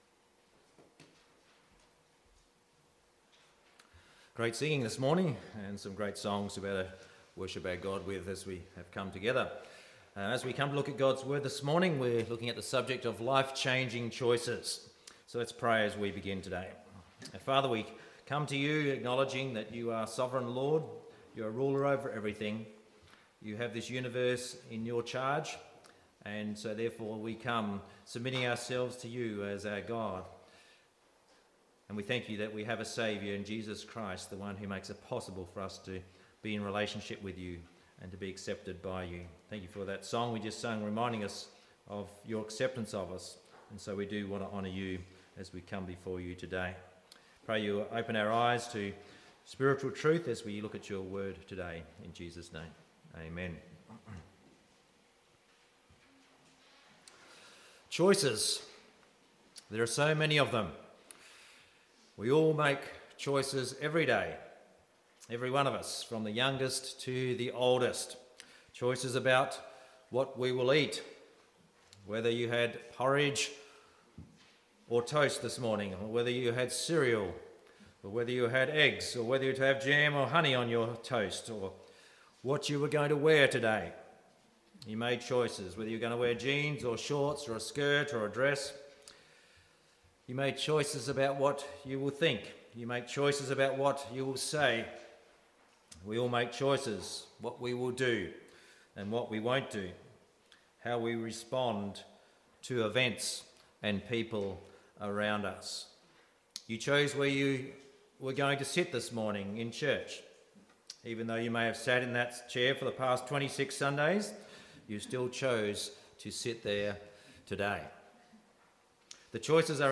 23.9.18-Sunday-Service-I-Choose-to-Receive-Gods-Love.mp3